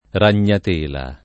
ragnatela [ ran’n’at % la ] s. f.